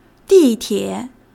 di4--tie3.mp3